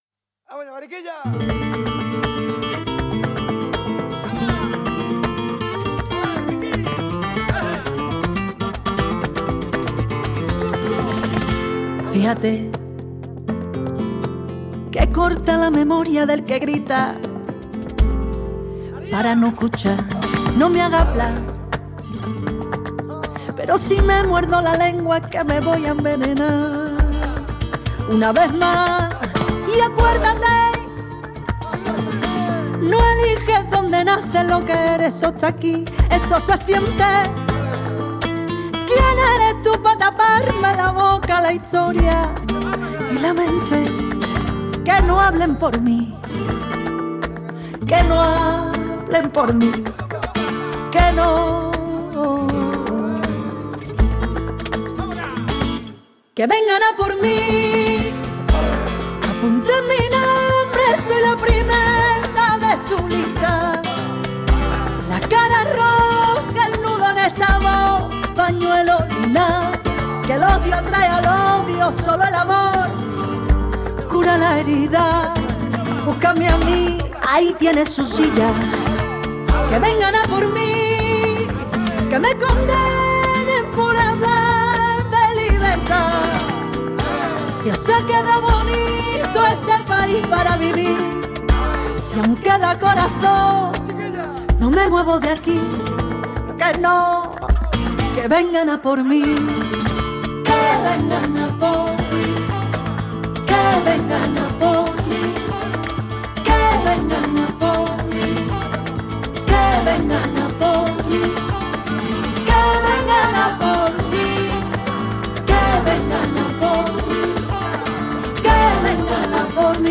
bulería